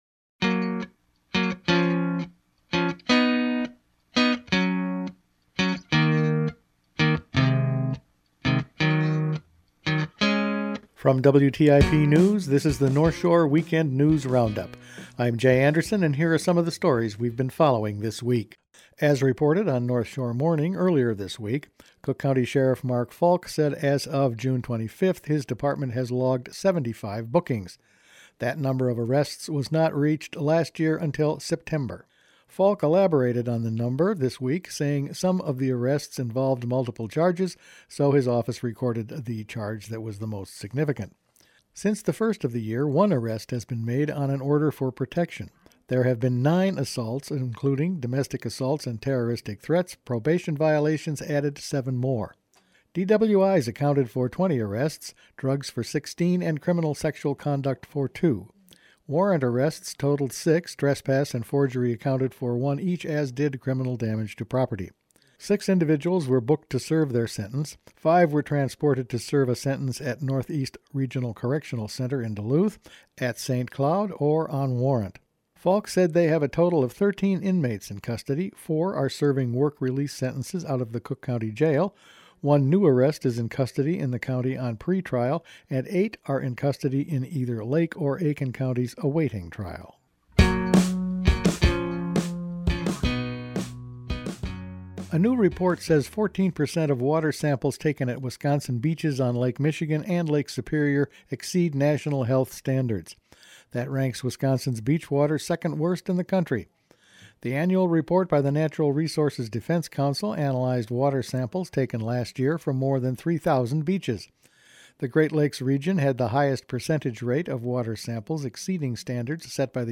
Weekend News Roundup for June 29
Each week the WTIP news staff puts together a roundup of the news over the past five days. Increased arrests in the county, bad water beaches in Wisconsin, a former CCHS Vikings player heads to Thunder Bay, water quality, wolves, pets and new courtroom restrictions in St. Louis County…all in this week’s news.